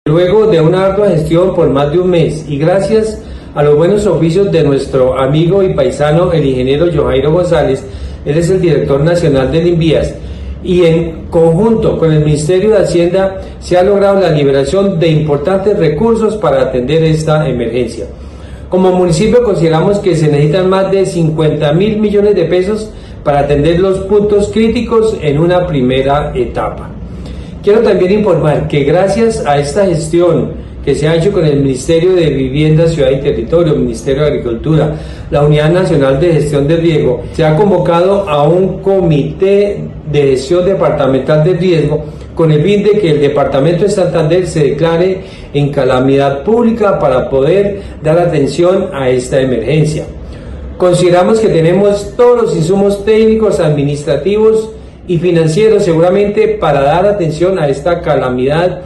Orlando Ariza, alcalde de Vélez